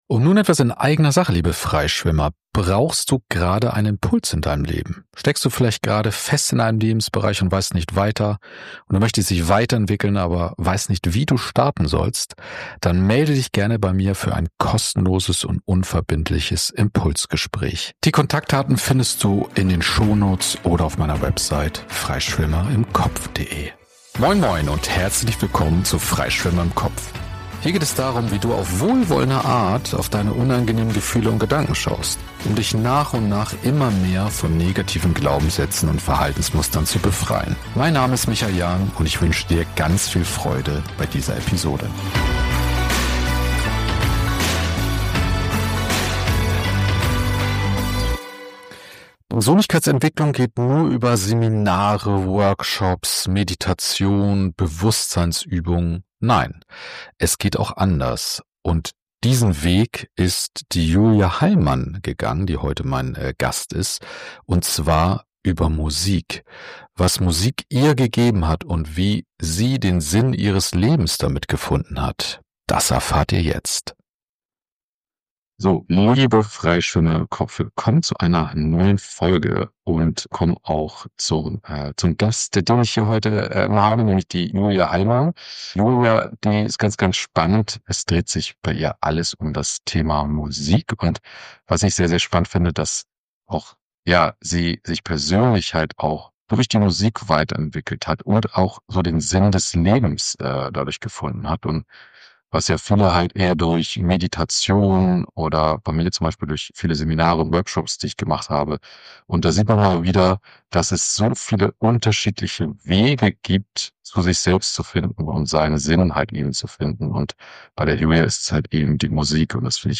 043: Vom Klang zur Erkenntnis: Wie Musik Selbstvertrauen und Sinn stiftet - Im Gespräch mit der Musikerin